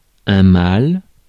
Ääntäminen
Ääntäminen : IPA : /sɪn/ US : IPA : [sɪn]